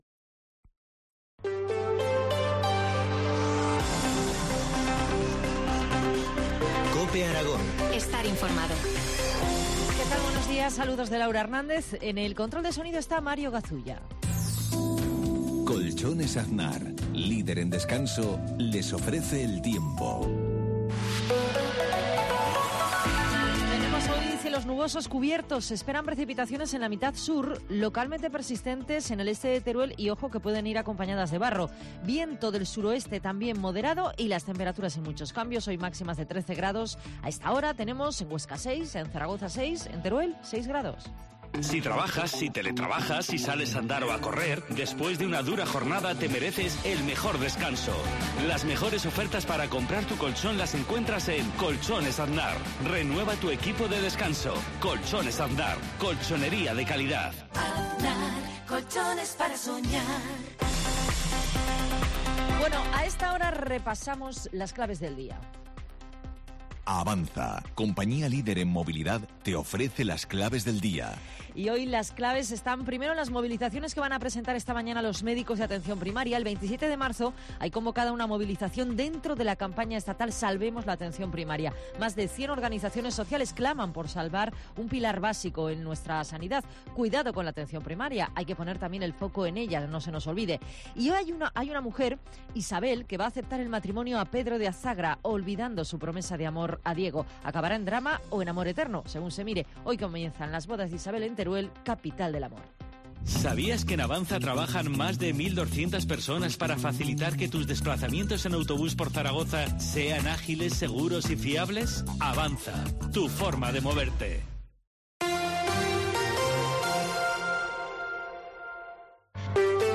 La Mañana en COPE Huesca - Informativo local